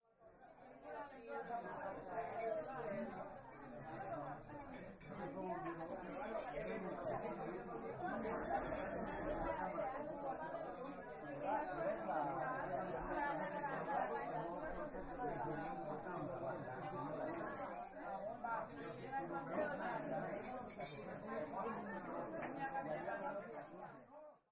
描述：餐厅里的环境声音。
Tag: 午餐 餐厅